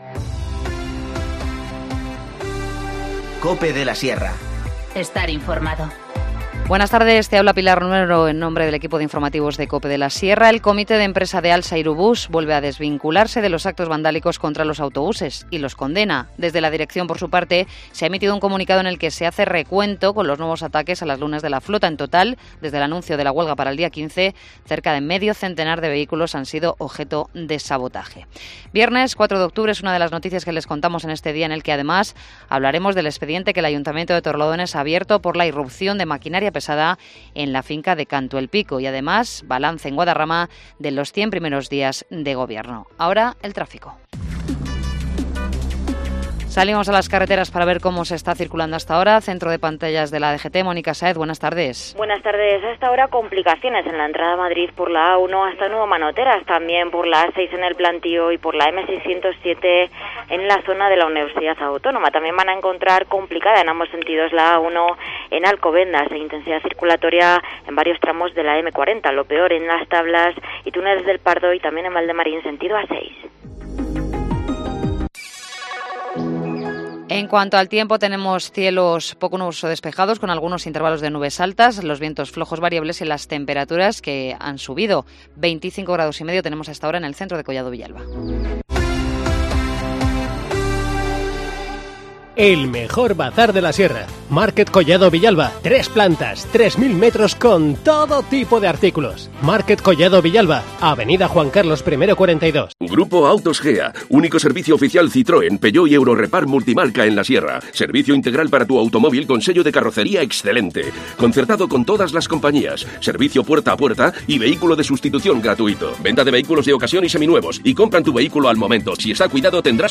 Informativo Mediodía 4 octubre 14:20h